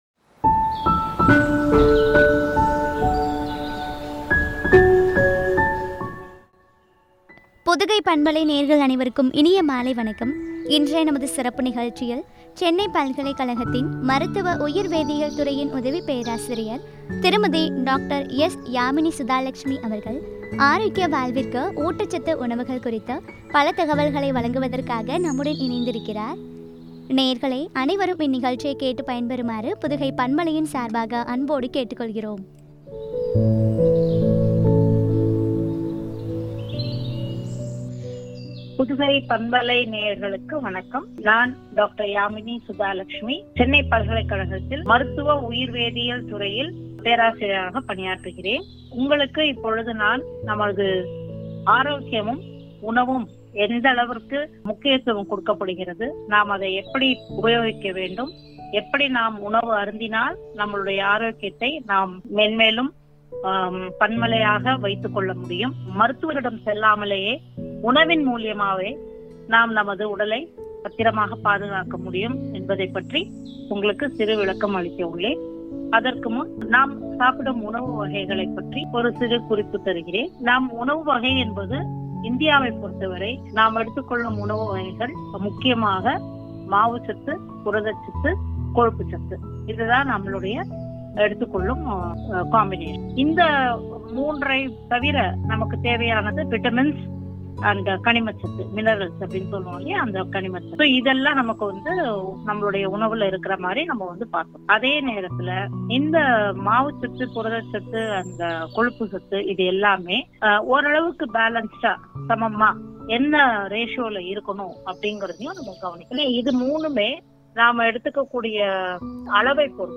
ஆரோக்கிய வாழ்விற்கு ஊட்டச்சத்து உணவுகள் பற்றிய உரையாடல்.